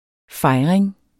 Udtale [ ˈfɑjʁeŋ ]